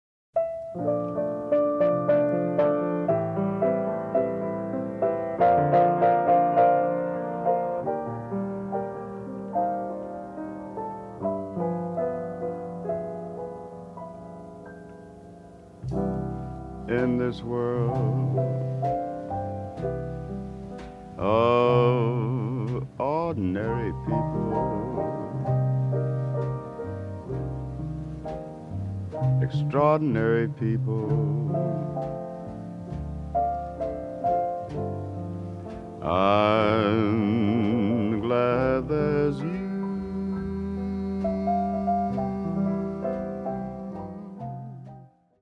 ラジオ番組のために録音された音源なのだそう。